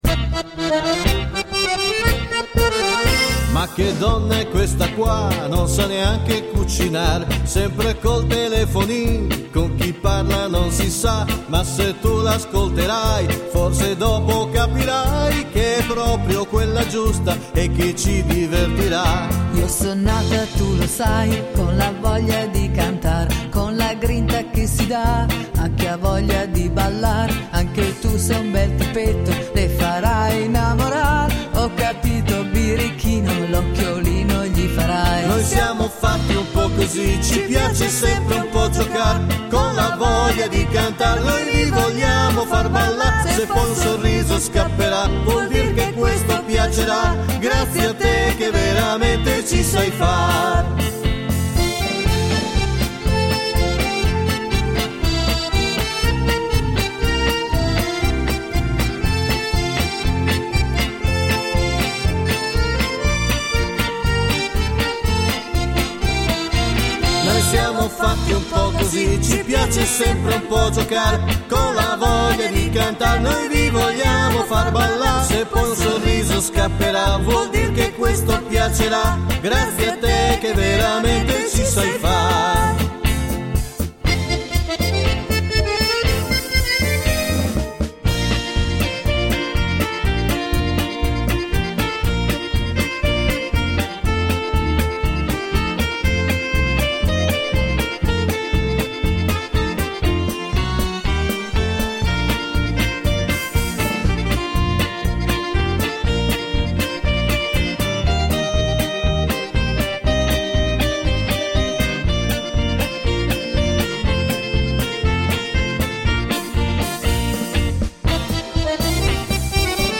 polka per voci fisa e orchestra